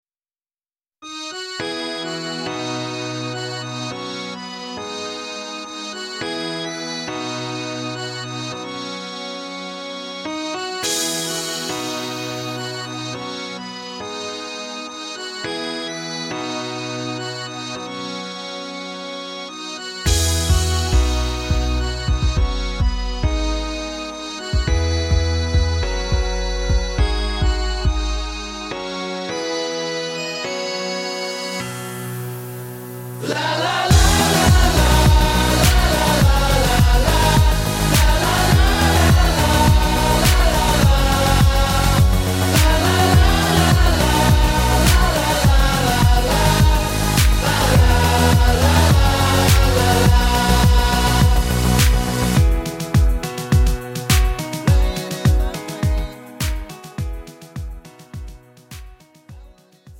음정 원키
장르 pop 구분